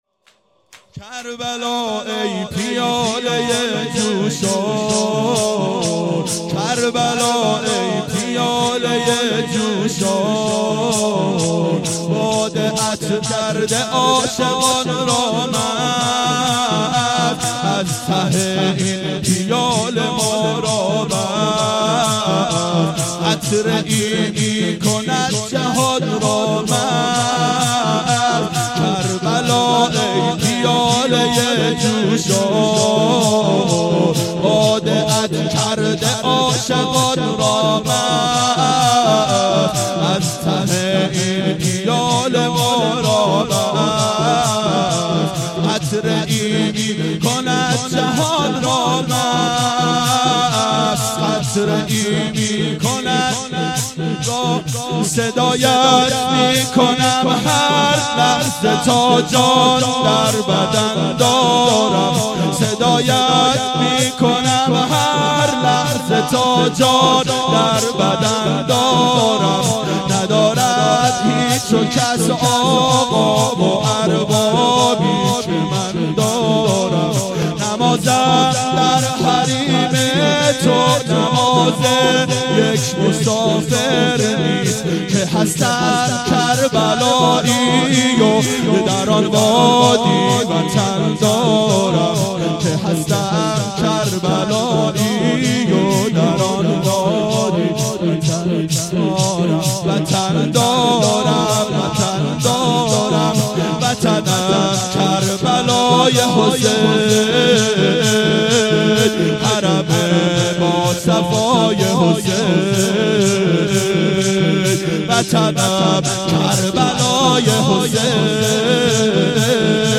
• دهه اول صفر سال 1391 هیئت شیفتگان حضرت رقیه سلام الله علیها (شب شهادت)